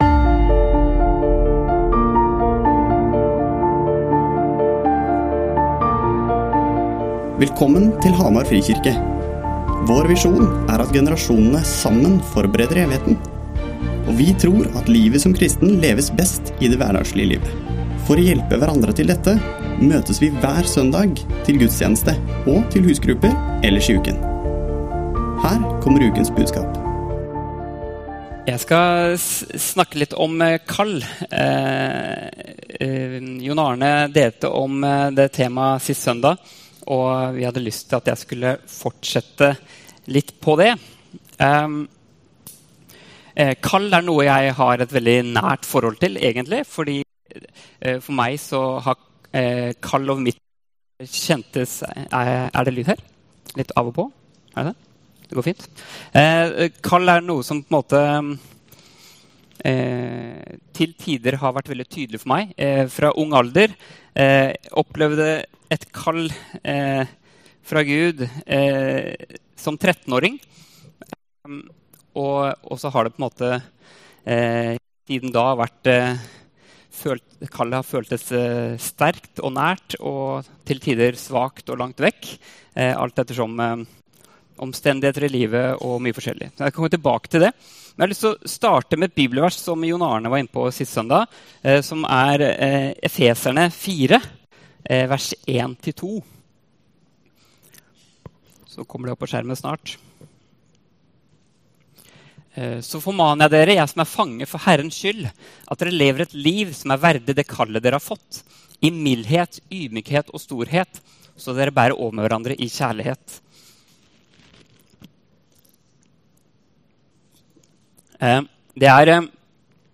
Gudstjenesten